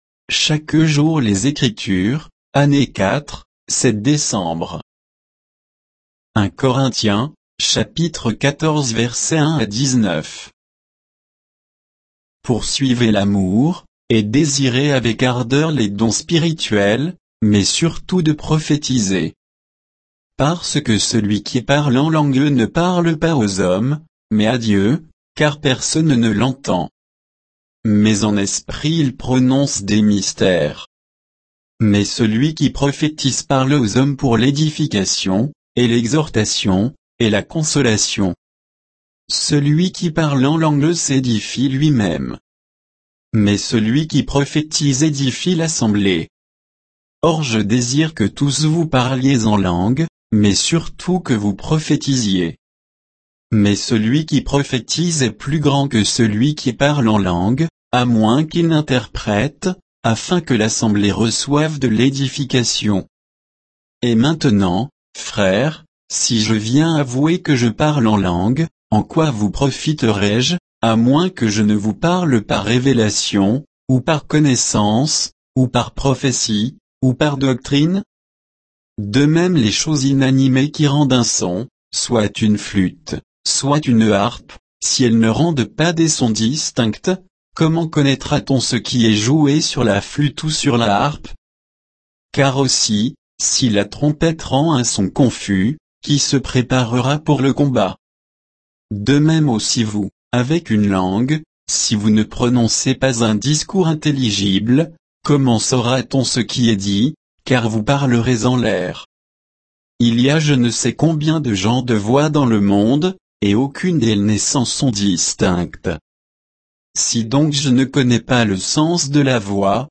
Méditation quoditienne de Chaque jour les Écritures sur 1 Corinthiens 14